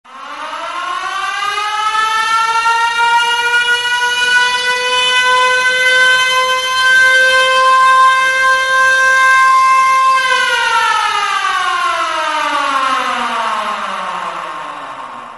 sirenaa.wav